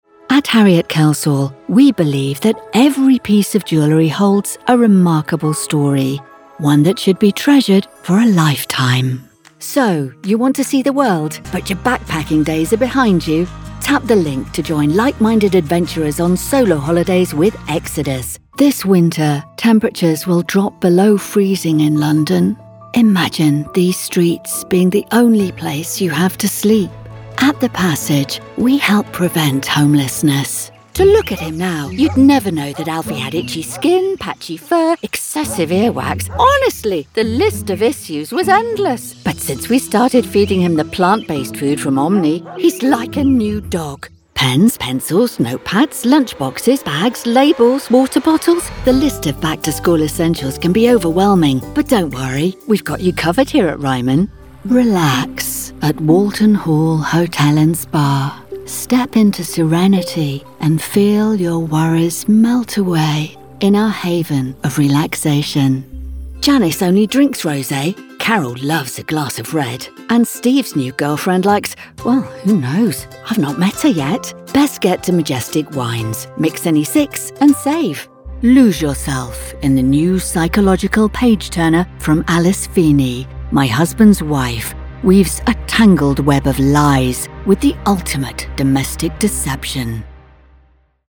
Ohio Department of Job and Family Services Voice Over Commercial Actor + Voice Over Jobs